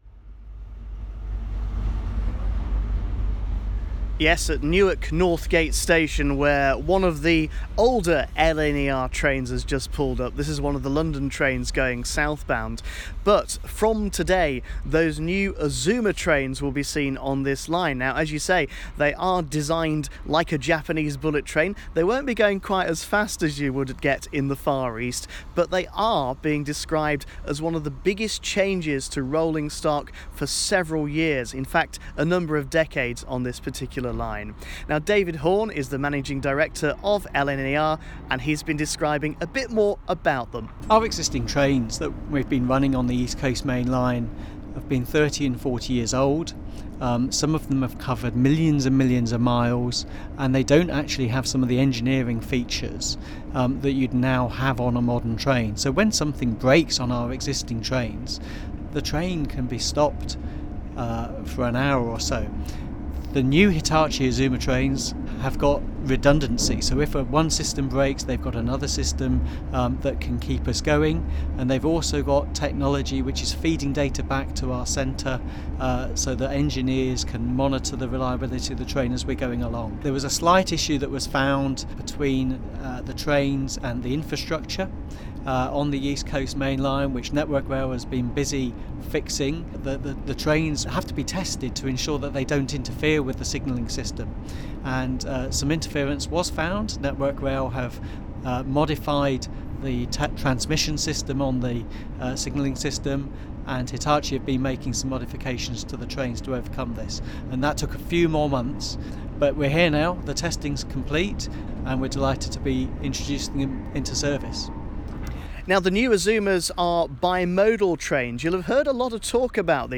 trains.wav